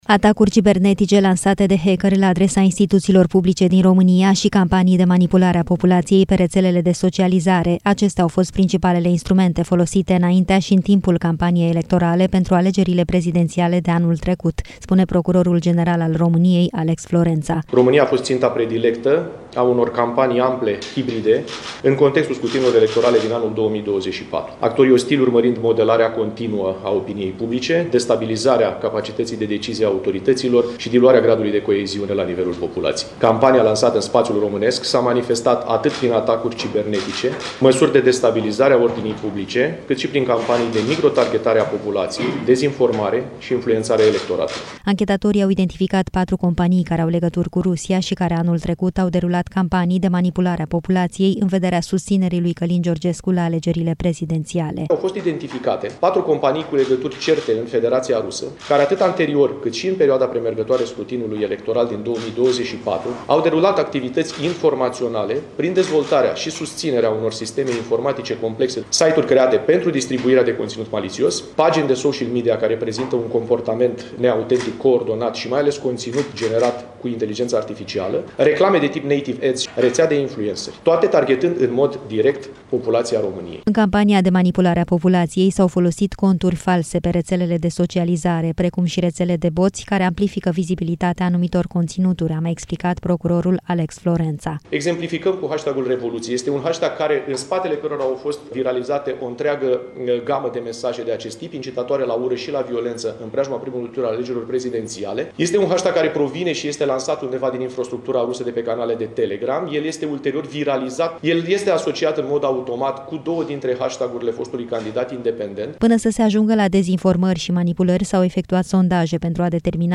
România a fost și este ținta atacurilor hibride coordonate din Rusia, spune procurorul general al României. Alex Florența  a explicat, astăzi, într-o conferință de presă mecanismele prin care Rusia a încercat să manipuleze populația pentru a susține un anumit candidat la alegerile prezidențiale de anul trecut.